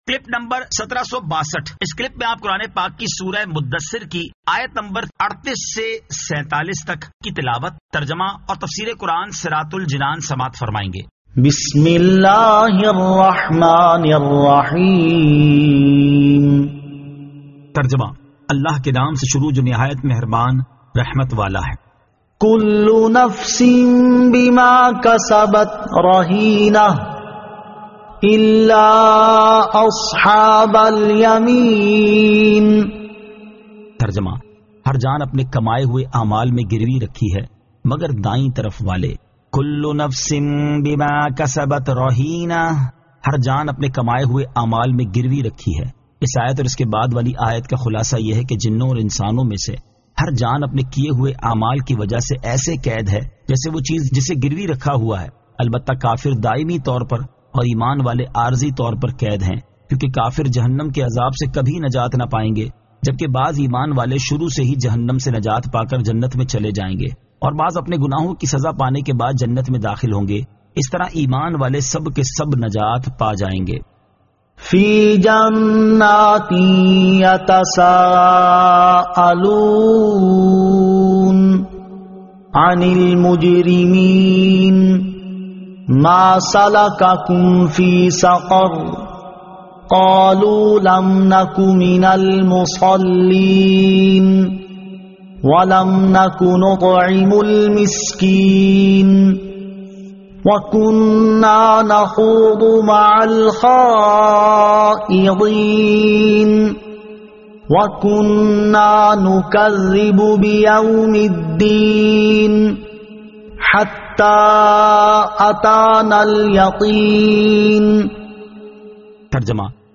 Surah Al-Muddaththir 38 To 47 Tilawat , Tarjama , Tafseer